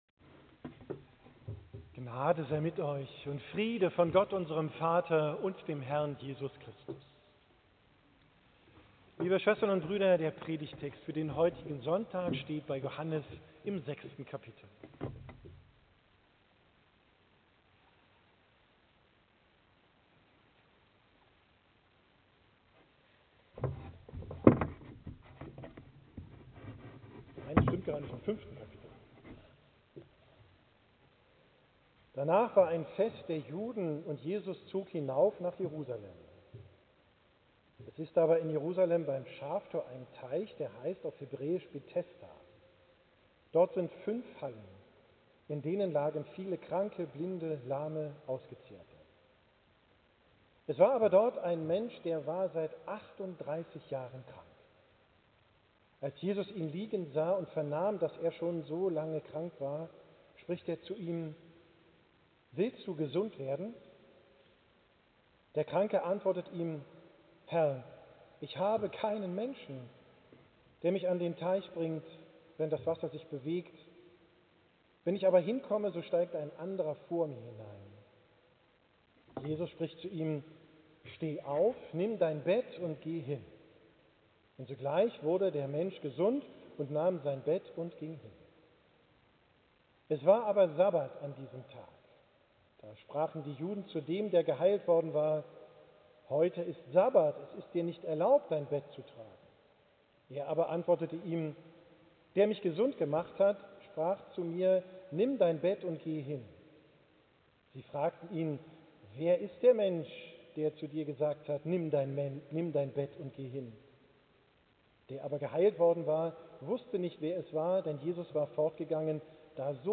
Predigt vom19. Sonntag nach Trinitatis, 26. X 2025